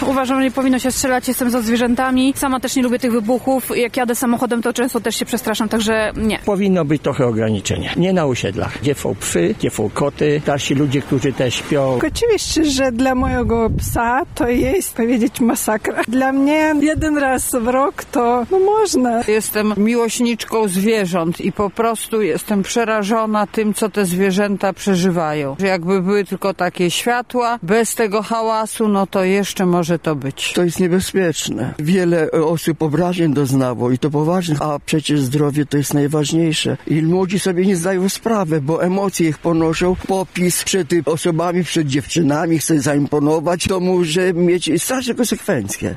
Poprosiliśmy mieszkańców Lublina o podzielenie się swoją opinią i doświadczeniem na temat puszczania fajewerków w ostatnią zabawę roku:
SONDA